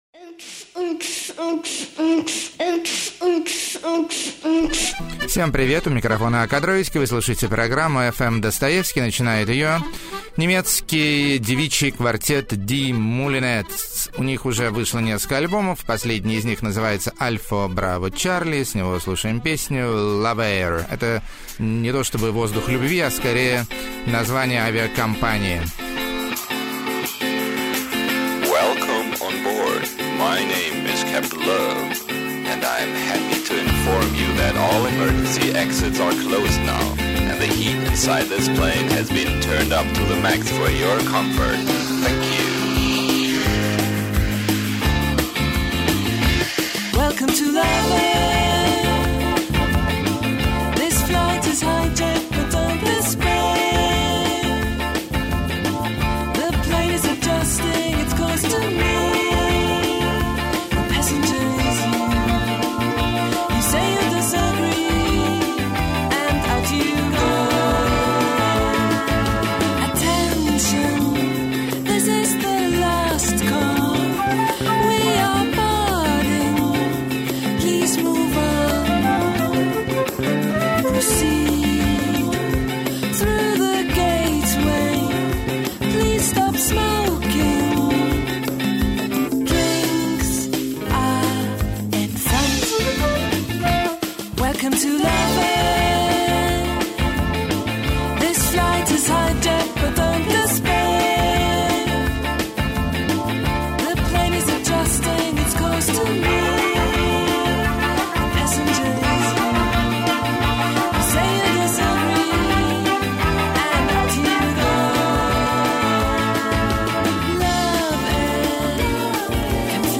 Милый Альбом Домашней Lo-fi Электроники.
Этно-даб.
[Чистый Декадентский Свинг.] 8.
Гипнотическая Фолк-электроника.
Сентиментально-ориентально-футуристично.
Компьютерно-вибрафонное Трио С Солисткой Типа Русалка.